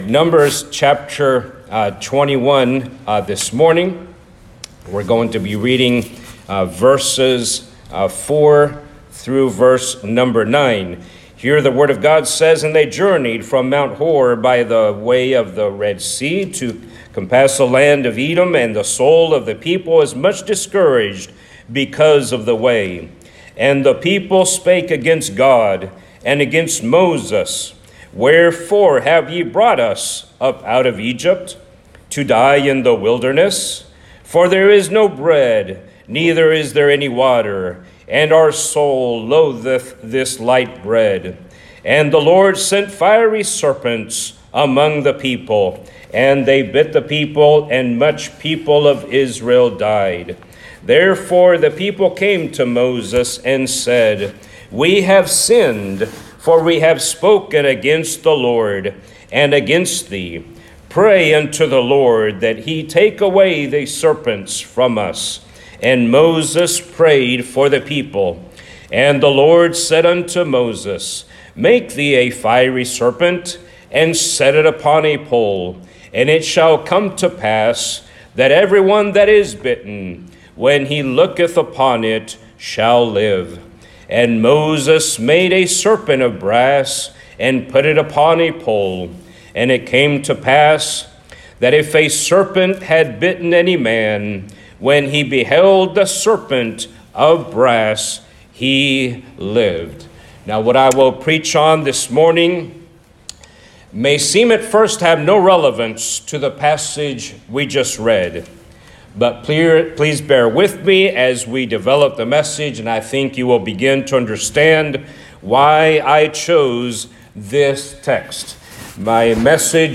Some recent sermons